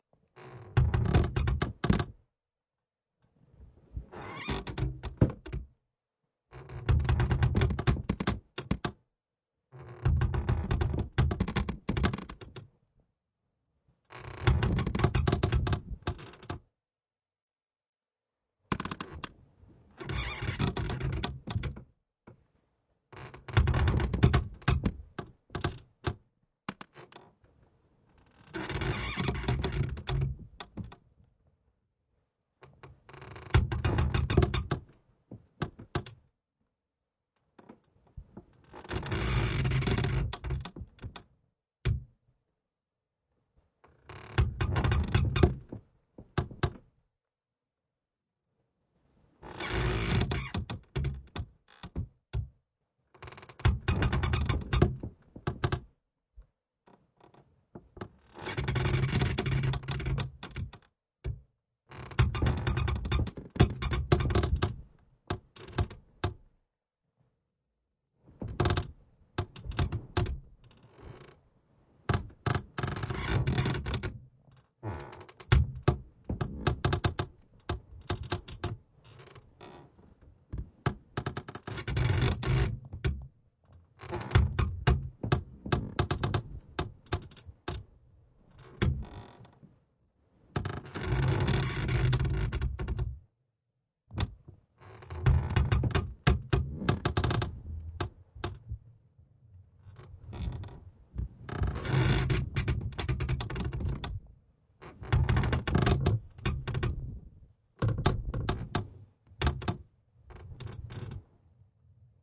foley " 床的吱吱声很多
Tag: 吱吱声 卧室里的床 睡觉 尖叫声 尖叫声 很多 吱吱作响 弗利